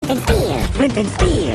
boss_shoot.mp3